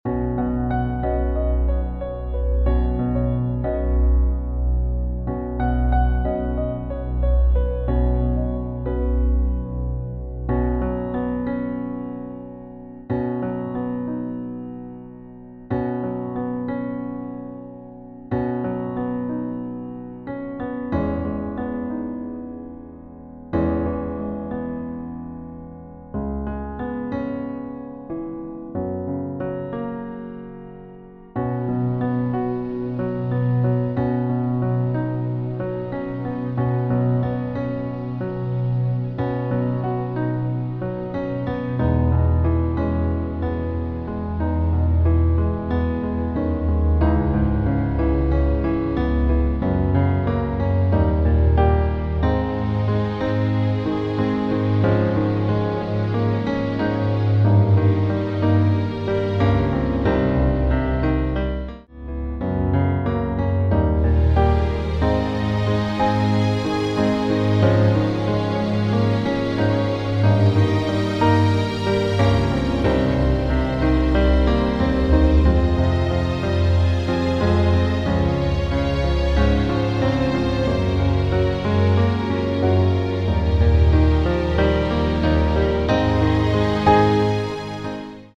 Demo in H Dur:
• Das Instrumental beinhaltet NICHT die Leadstimme
• Das Instrumental enthält KEINEN Text
Klavier / Piano